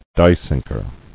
[die·sink·er]